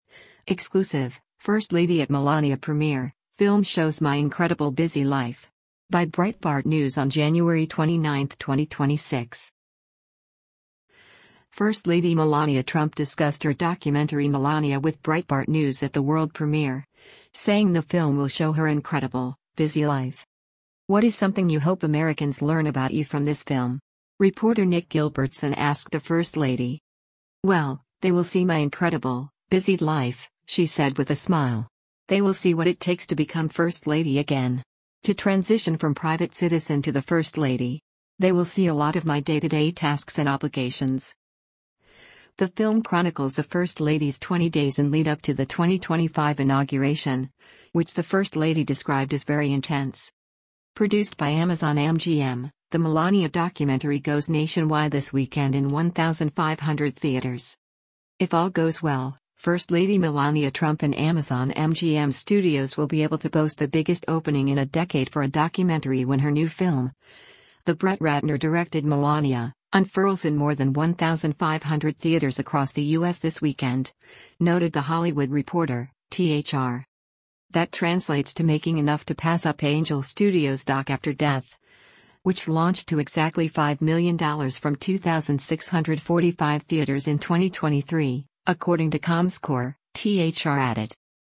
First Lady Melania Trump discussed her documentary Melania with Breitbart News at the world premiere, saying the film will show her “incredible, busy life.”